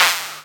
VEC3 Percussion 028.wav